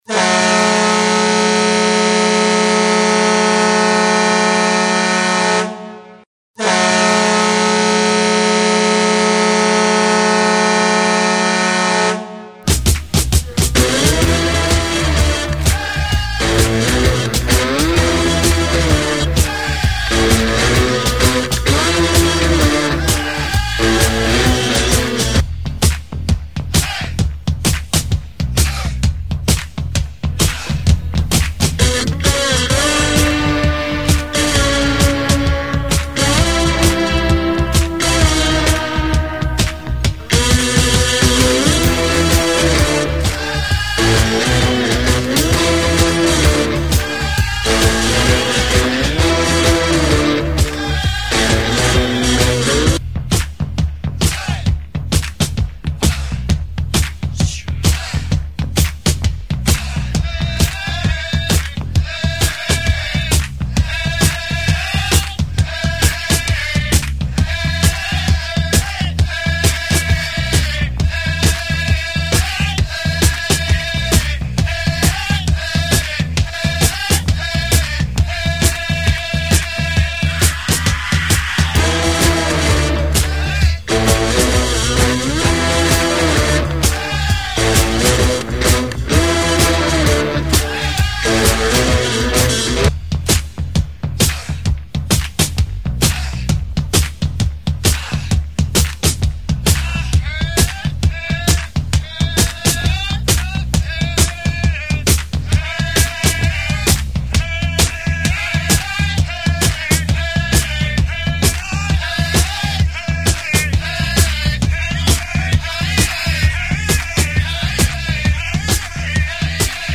Category: Sports   Right: Personal
Tags: Princeton Hockey Baker Rink